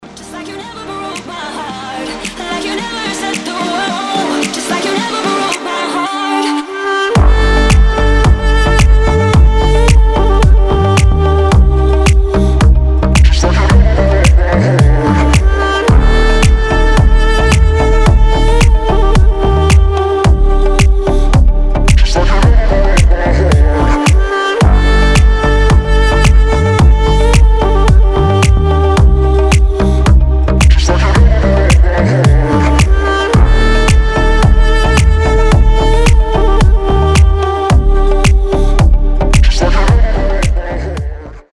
• Качество: 320, Stereo
мужской голос
женский вокал
deep house
восточные мотивы
красивая мелодия
дудук
Красивый восточный deep house